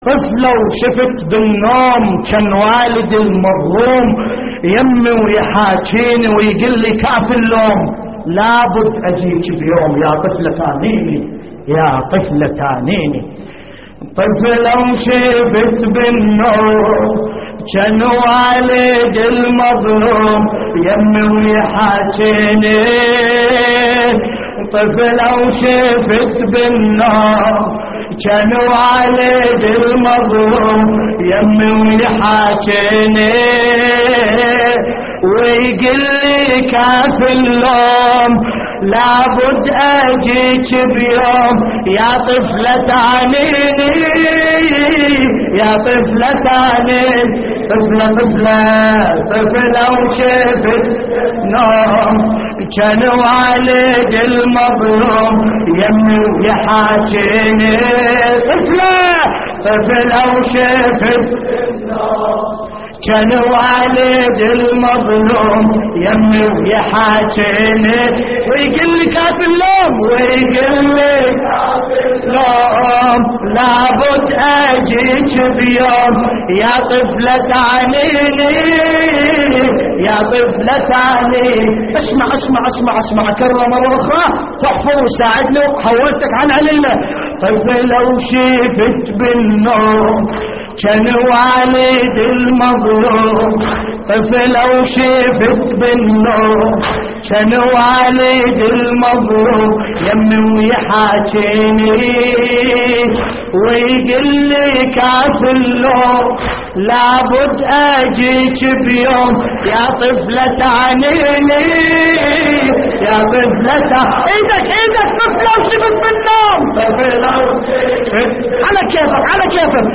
تحميل : طفلة وشفت بالنوم كأن والدي المظلوم / الرادود باسم الكربلائي / اللطميات الحسينية / موقع يا حسين